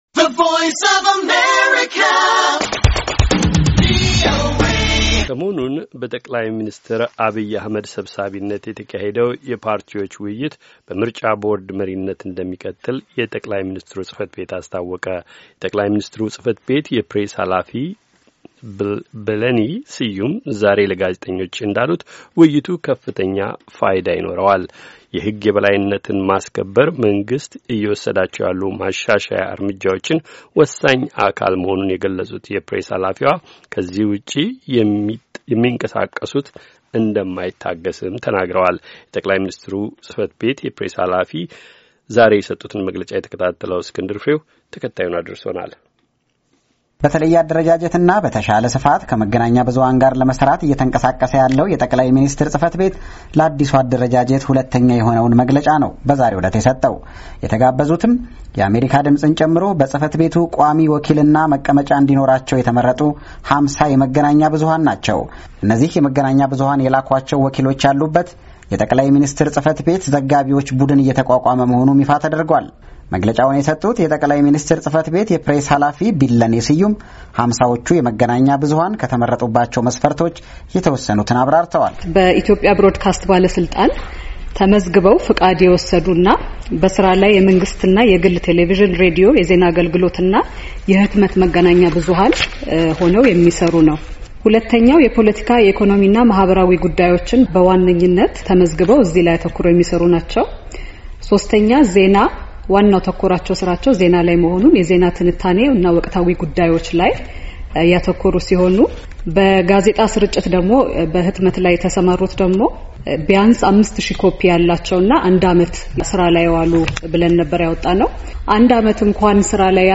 የጠ/ሚኒስትር ጽ/ቤት የፕሬስ ኃላፊ በወቅታዊ ጉዳይ መግለጫ